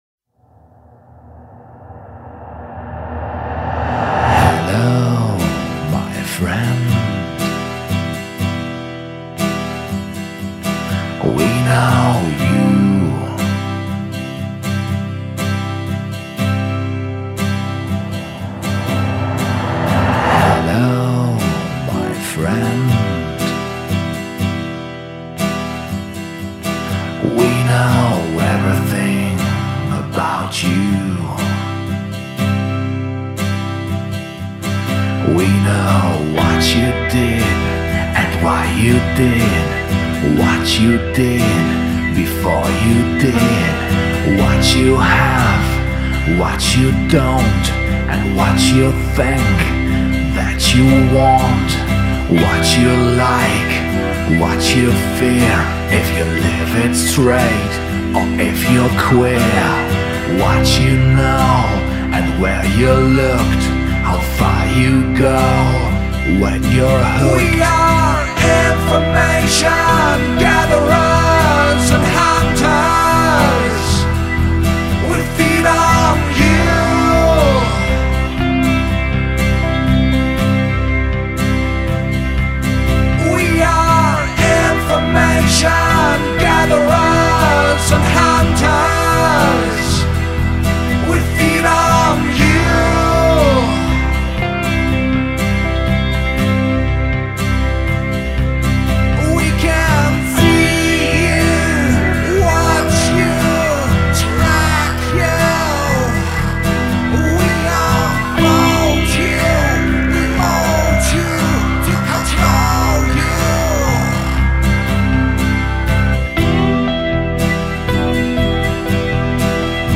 Der Übergang auf dem Album war so gestaltet, dass zwischen den Liedern eine ruhige Phase war um dann wieder mit kräftigen Drums einzusteigen…